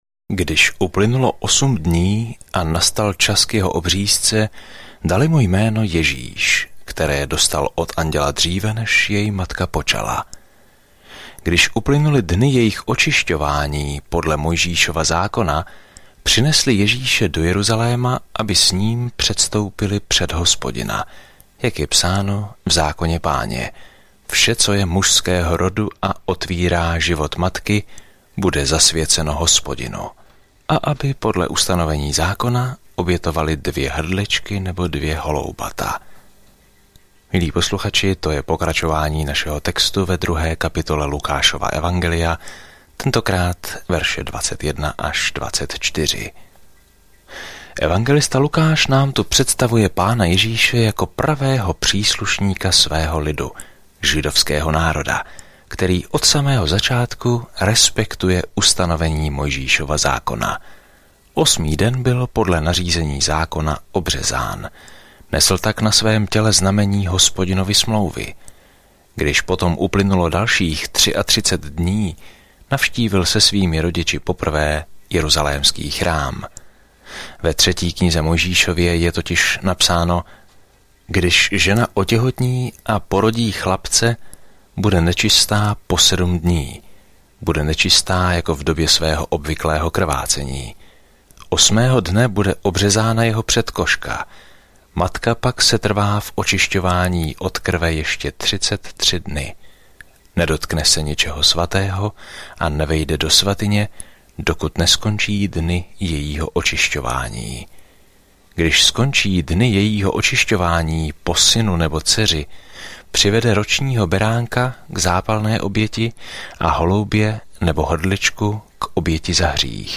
Písmo Lukáš 2:21-52 Lukáš 3:1 Den 4 Začít tento plán Den 6 O tomto plánu Očití svědkové informují o dobré zprávě, kterou Lukáš vypráví o Ježíšově příběhu od narození po smrt až po vzkříšení; Lukáš také převypráví své učení, které změnilo svět. Denně procházejte Lukášem, když posloucháte audiostudii a čtete vybrané verše z Božího slova.